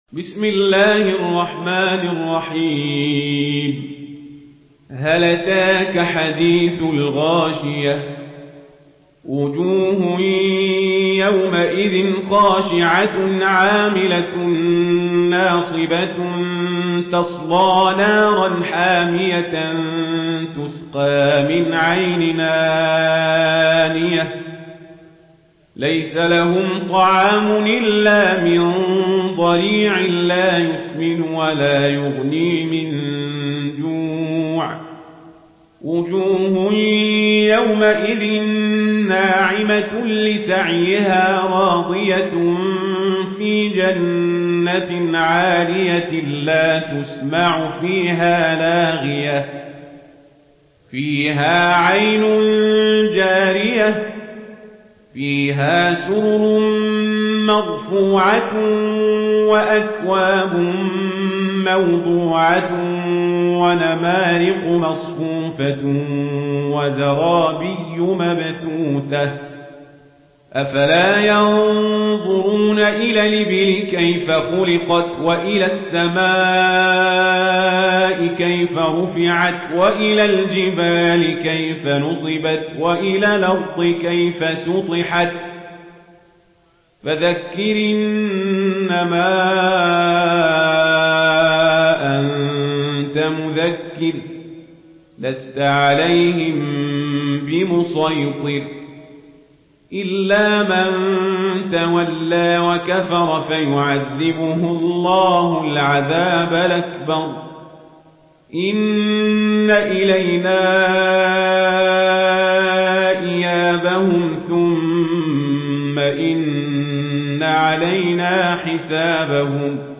تحميل سورة الغاشية بصوت عمر القزابري
مرتل ورش عن نافع